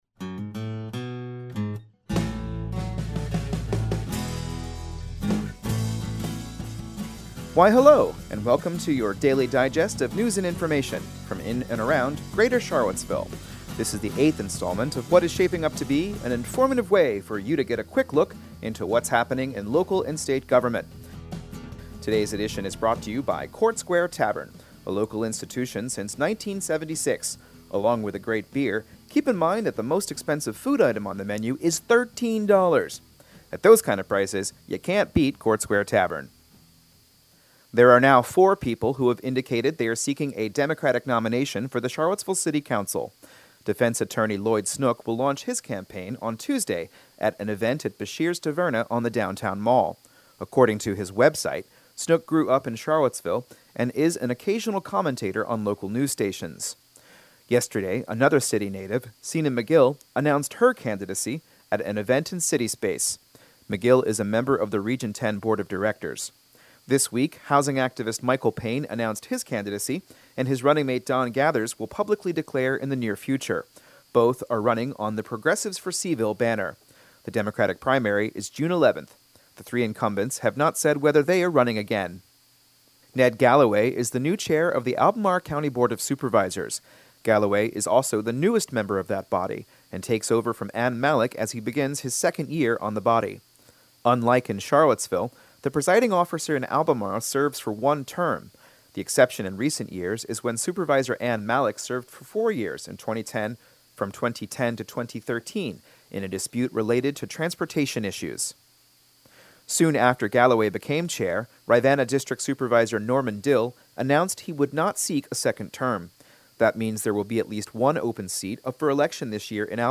Newscast for January 10, 2019